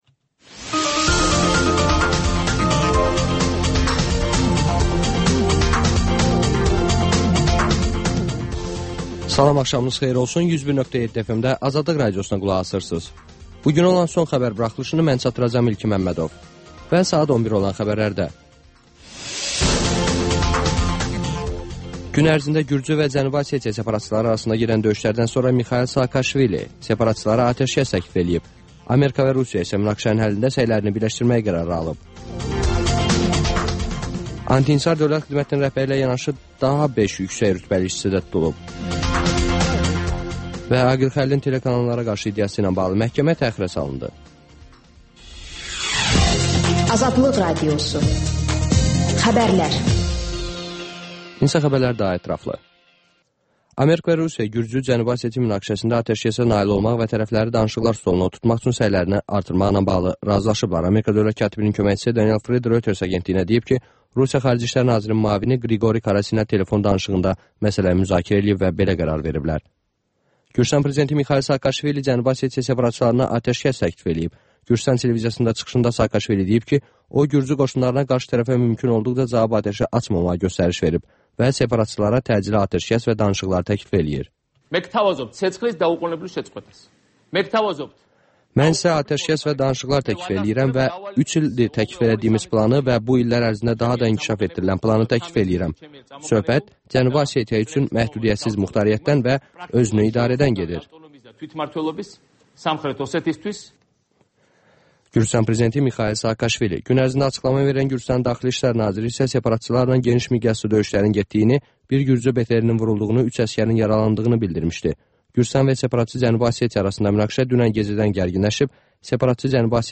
Xəbərlər, RAP-TIME: Gənclərin musiqi verilişi